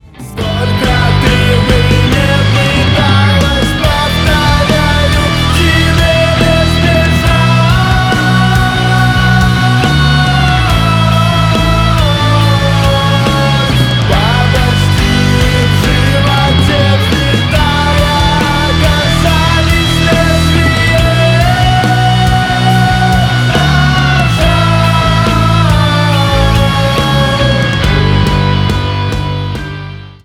Рингтоны Альтернатива
Рок Металл Рингтоны